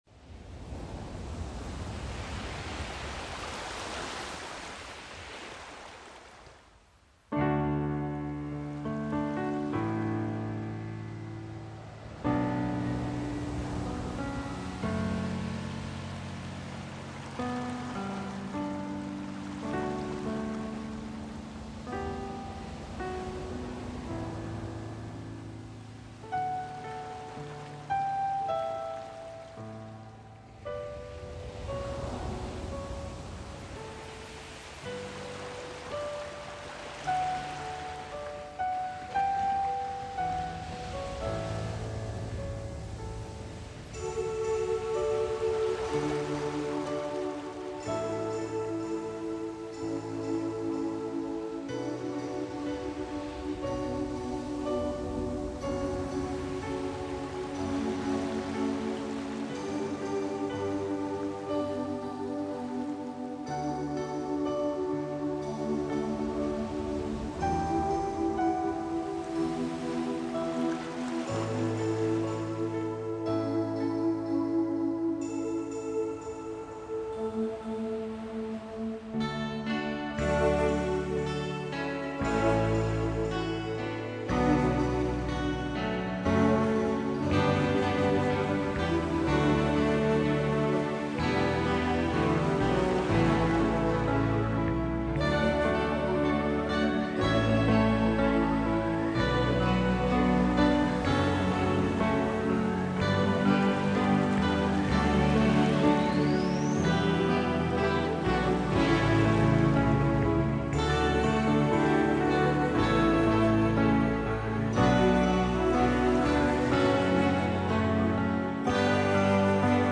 Genre Música para El Alma